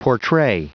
Prononciation du mot portray en anglais (fichier audio)
Prononciation du mot : portray